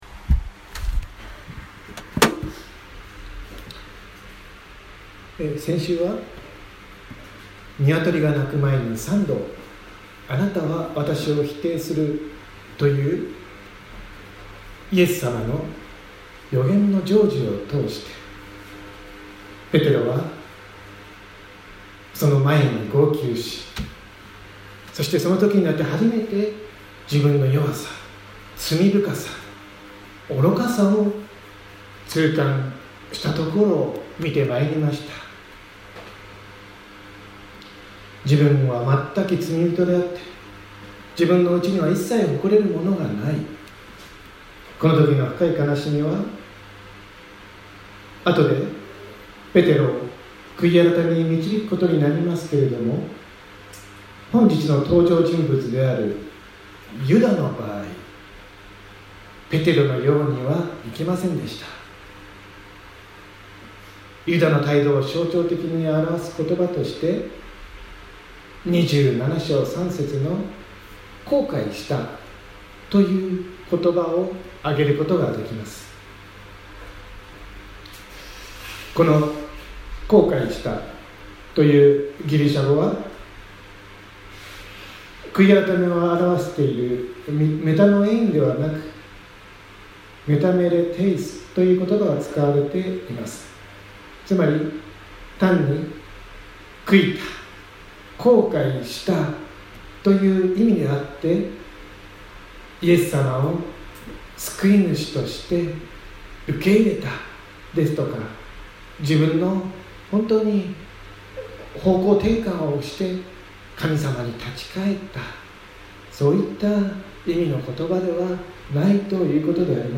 2019年07月14日朝の礼拝「血の畑 피밭」せんげん台教会
千間台教会。説教アーカイブ。